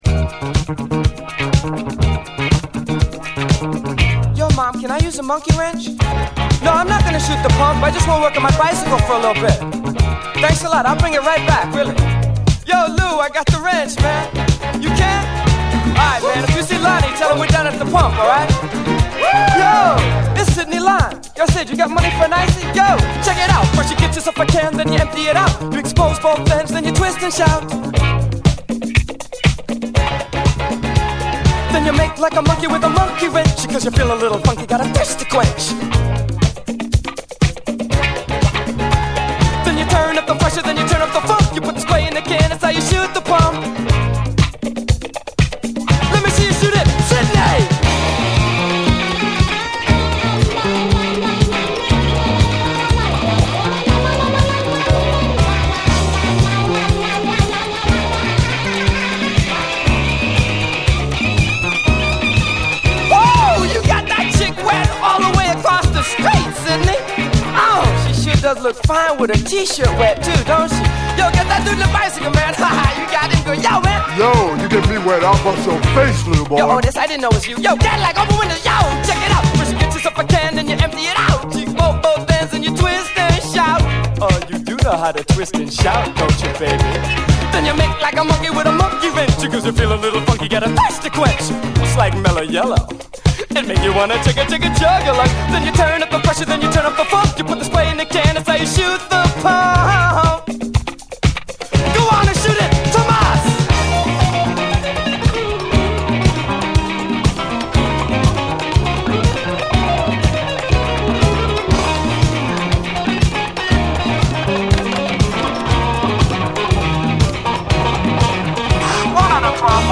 81年NY産、最高のラテン・ディスコ系マイナー・オールドスクール・ディスコ・ラップ！躍動感がハンパでない！